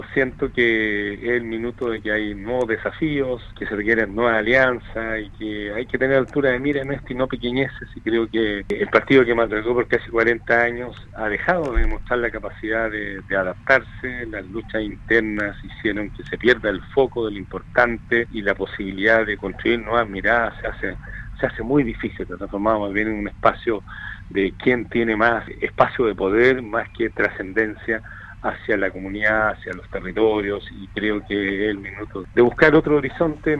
En conversación con Radio Sago, el Gobernador de la región de Los Lagos, Patricio Vallespín, dio a conocer que dejará la militancia de la Democracia Cristiana.
La entrevista completa al Gobernador Vallespín: